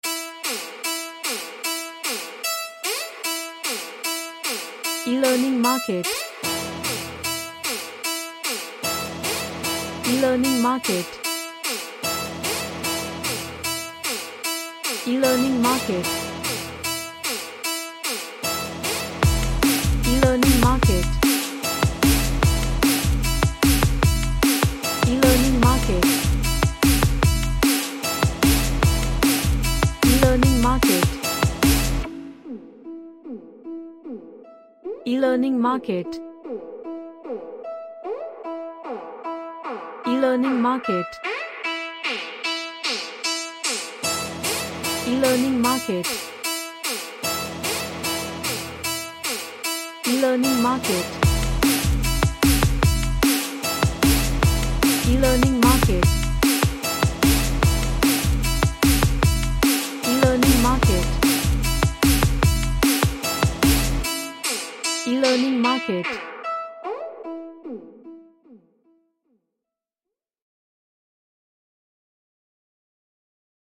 A hard and strange indie vibe
Strange / Bizarre